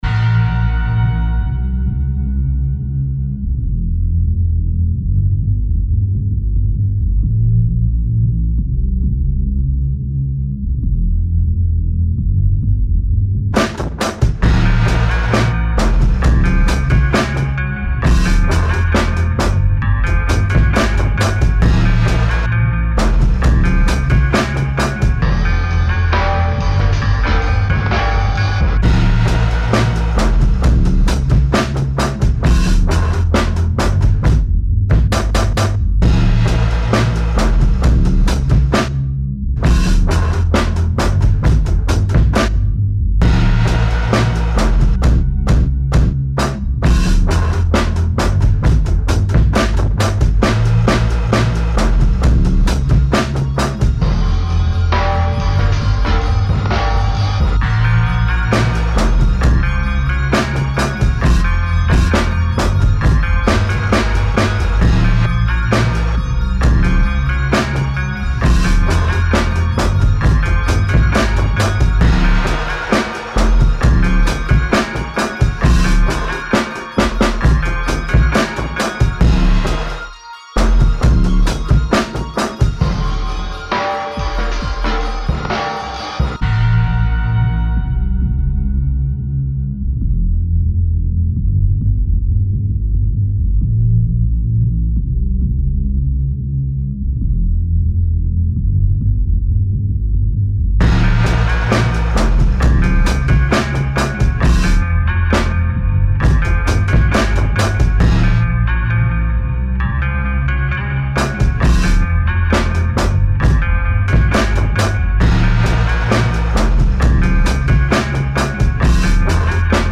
No scratching at all.